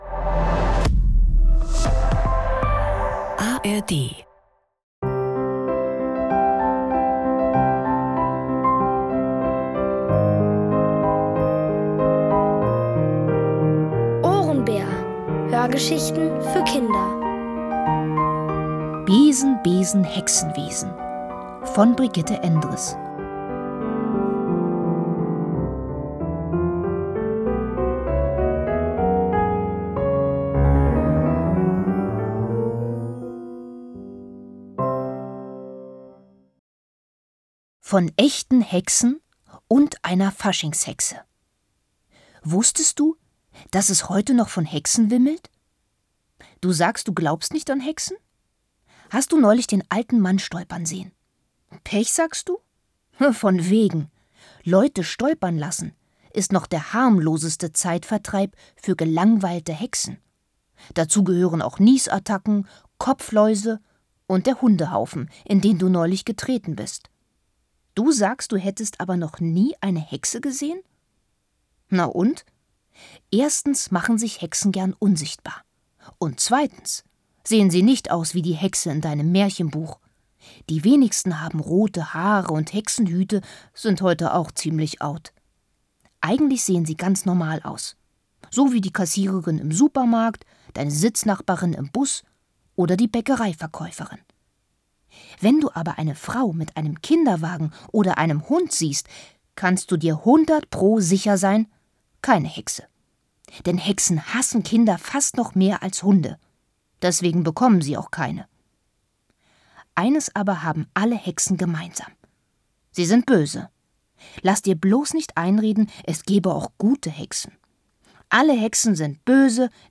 Alle 2 Folgen der OHRENBÄR-Hörgeschichte: Besen, Besen, Hexenwesen von Brigitte Endres.